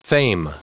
Prononciation du mot fame en anglais (fichier audio)
Prononciation du mot : fame